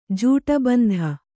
♪ jūṭa bandha